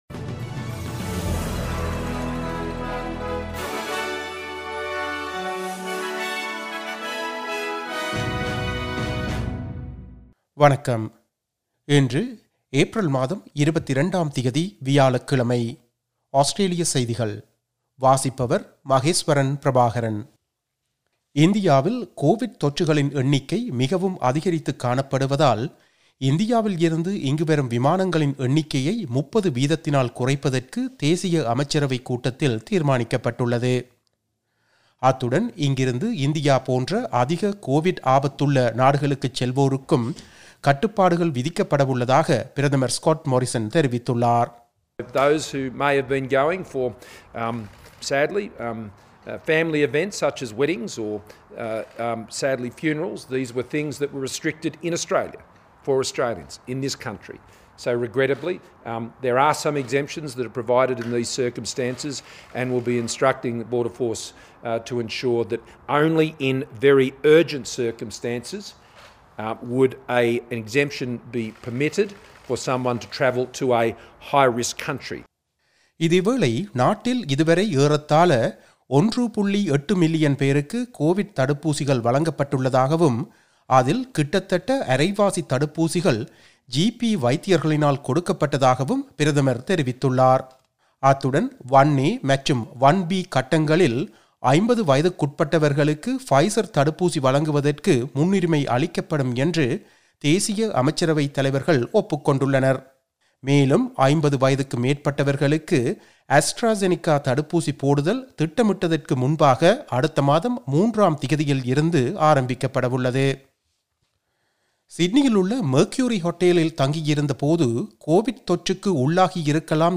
Australian news bulletin for Thursday 22 April 2021.